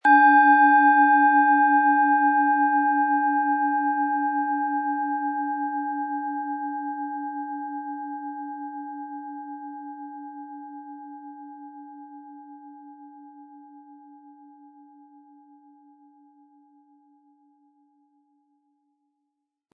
Planetenton 1
Sie sehen eine Planetenklangschale Mars, die in alter Tradition aus Bronze von Hand getrieben worden ist.
Lassen Sie die Klangschale mit dem kostenlosen Klöppel sanft erklingen und erfreuen Sie sich an der wohltuenden Wirkung Ihrer Mars.
MaterialBronze